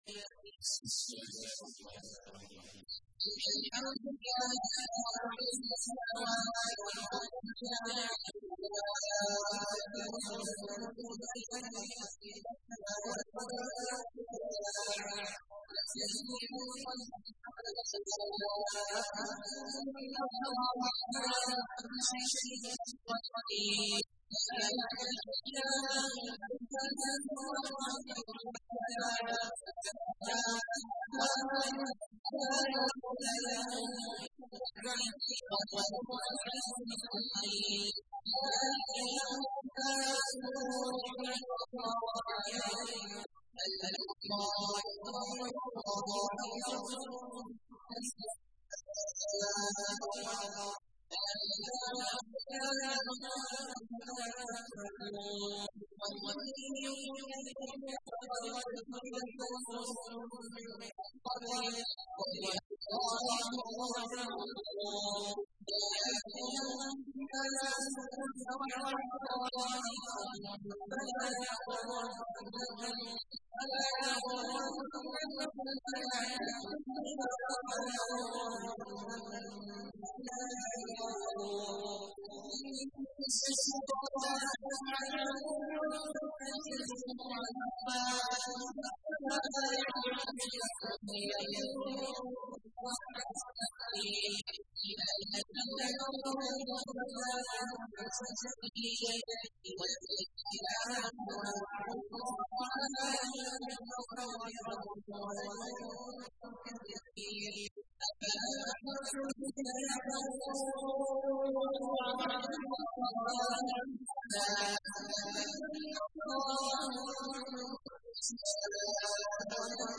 تحميل : 35. سورة فاطر / القارئ عبد الله عواد الجهني / القرآن الكريم / موقع يا حسين